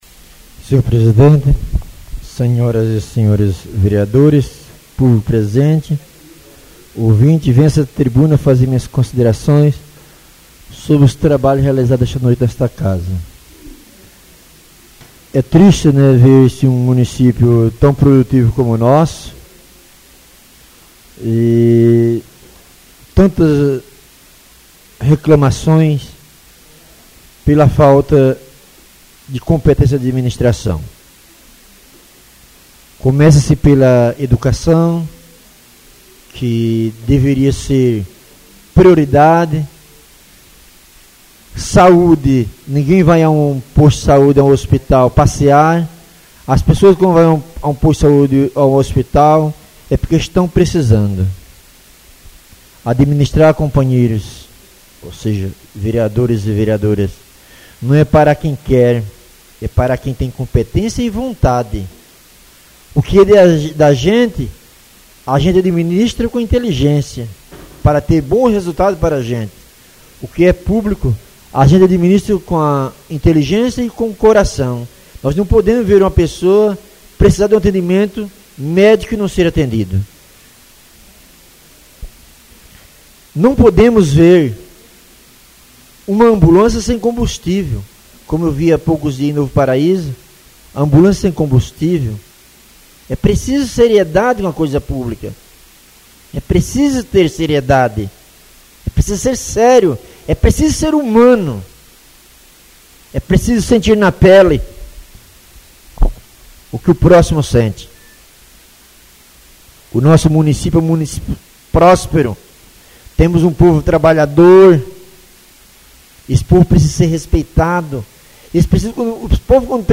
Oradores das Explicações Pessoais (28ª Ordinária da 3ª Sessão Legislativa da 6ª Legislatura)